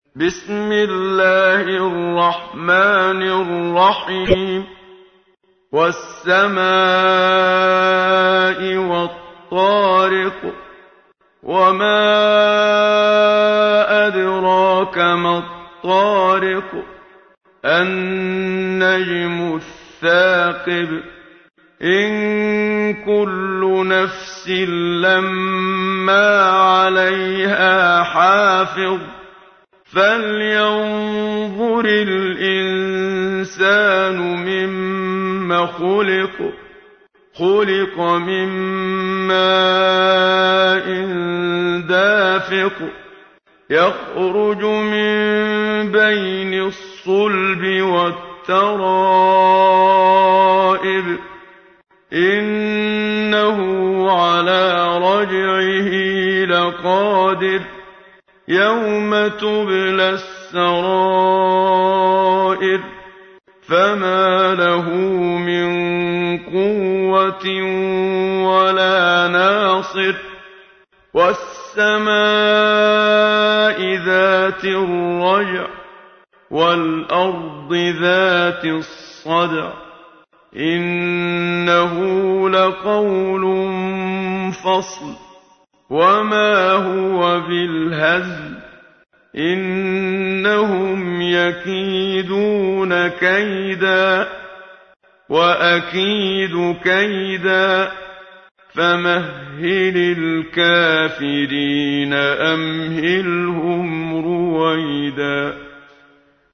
سورة الطارق / القارئ محمد صديق المنشاوي / القرآن الكريم / موقع يا حسين